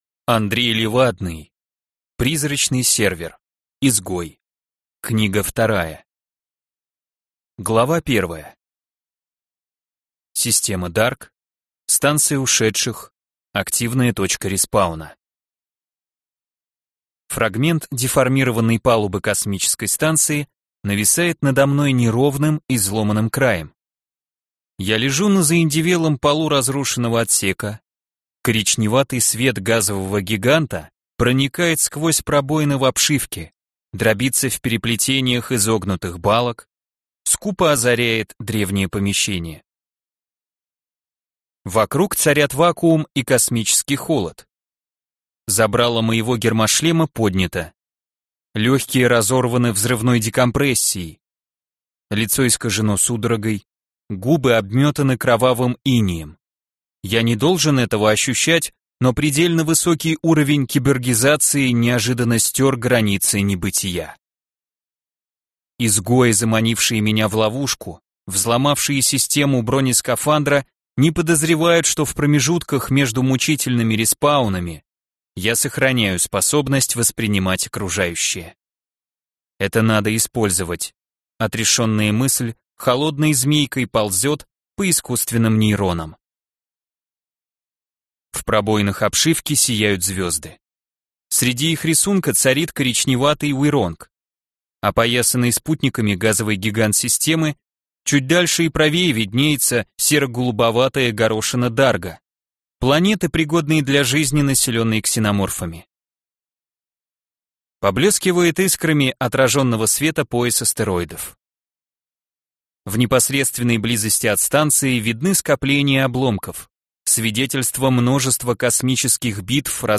Аудиокнига Призрачный Сервер. Изгой | Библиотека аудиокниг